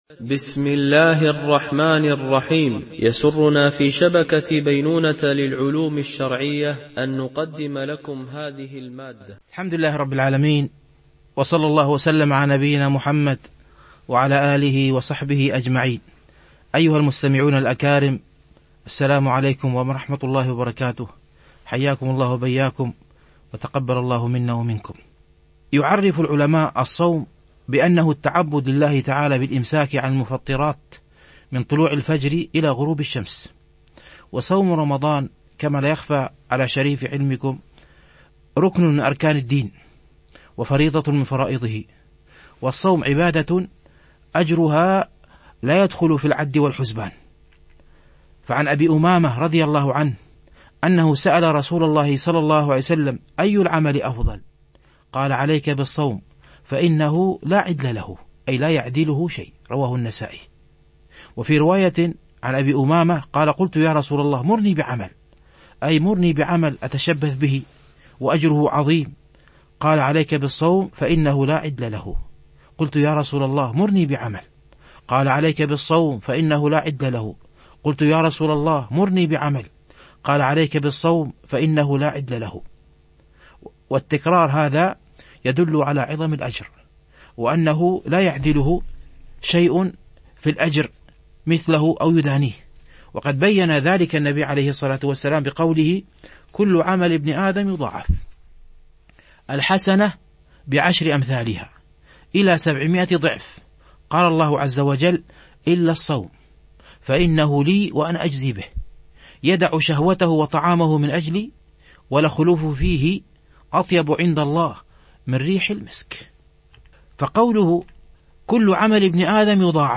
فقه الصائم - الدرس 2